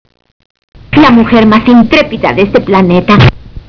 Por motivos de espacion estos sonidos estan hechos en formato wav de 8 bits, por eso es que no tienen mucha calidad, si quieres oir las versiones mas claras, solo Escribeme Y yo te mando los MP3 sin ningun problema.